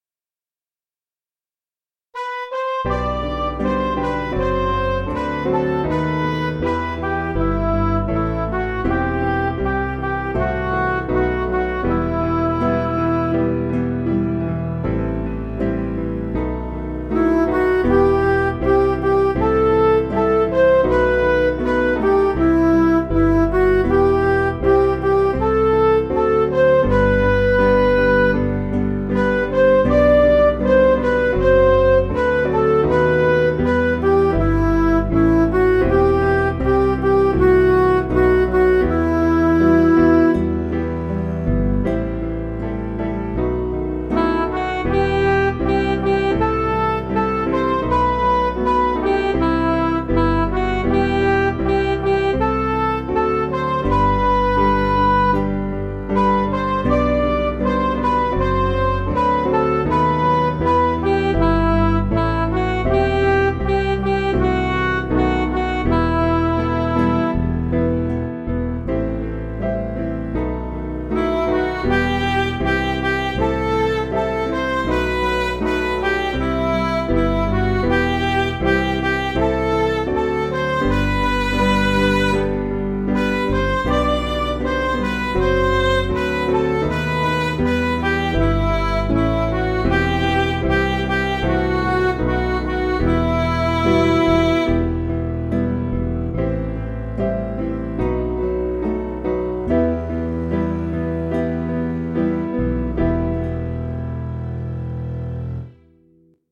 Piano & Instrumental
3/Em
Midi